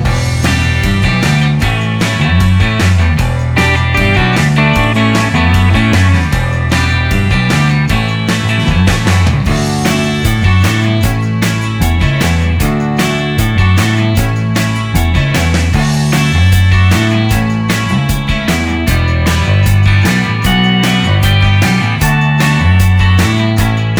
Minus Solo Guitar Pop (1980s) 3:47 Buy £1.50